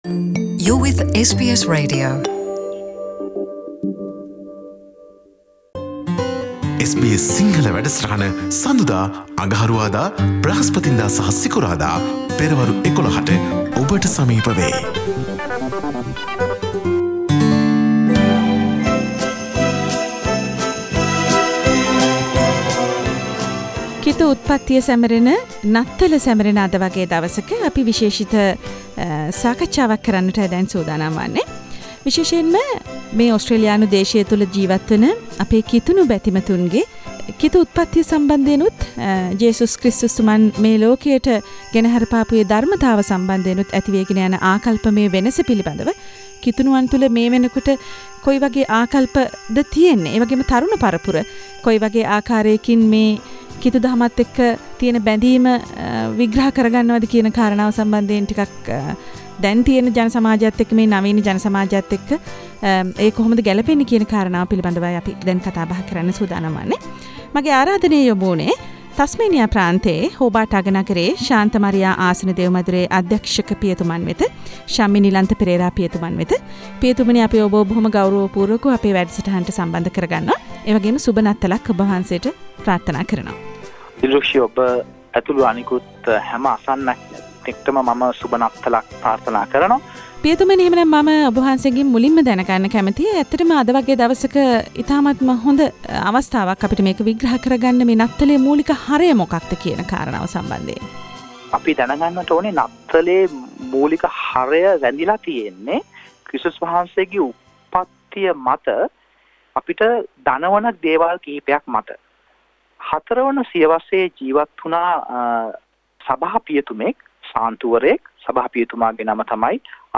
නවීන ලෝකය සමඟ පාවී යන නත්තලේ සැබෑ අරුත සහ ඔසී දේසේ බහුසංස්කෘතික කිතුනුවා : SBS සිංහල නත්තල් දින විශේෂ සාකච්චාව